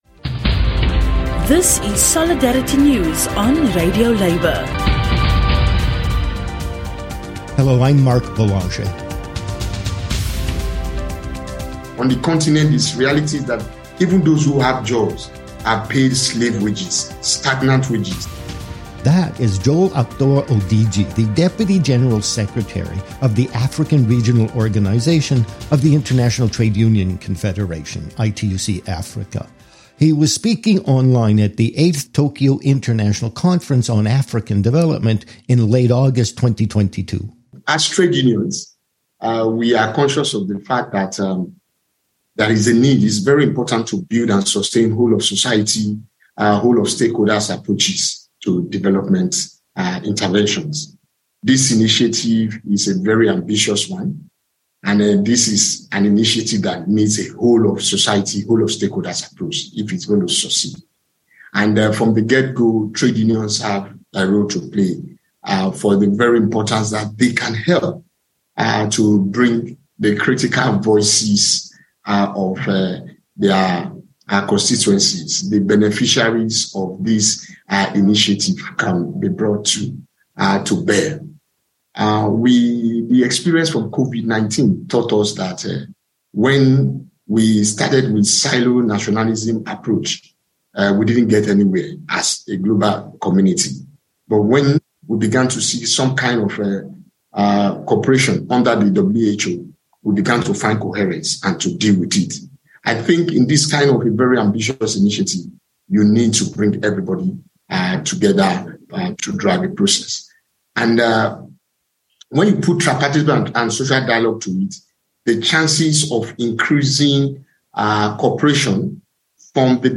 Even many of those who have jobs in Africa are paid slave-level wages. But the African Regional Organization of the International Trade Union Confederation has solutions. A presentation